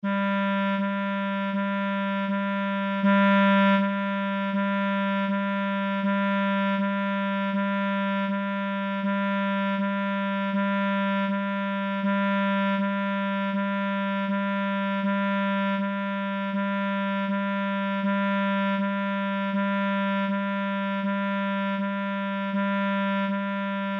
Velaquí atoparedes os arquivos de audio coas notas da escala musical:
Nota SOL